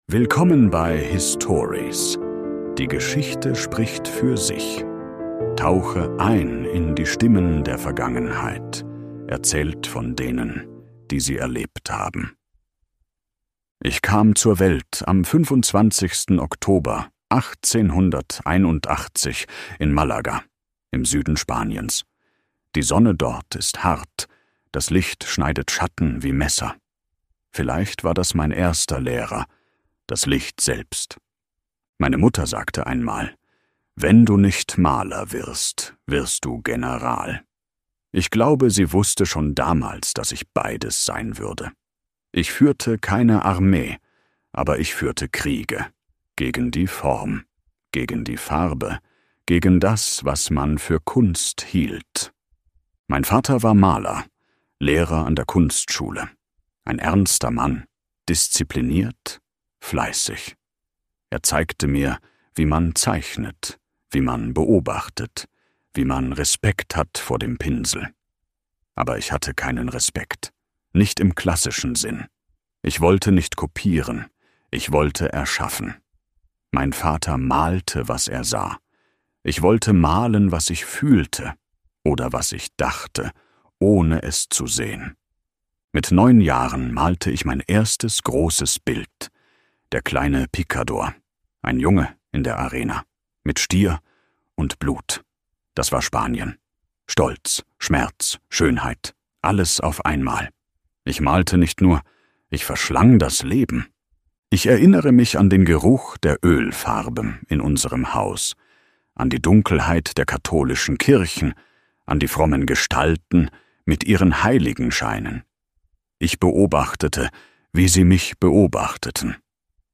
In dieser Folge von HISTORIES – Die Geschichte spricht für sich hörst du die Stimme eines Genies, das keine Regeln kannte.